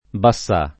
bassà [ ba SS#+ ]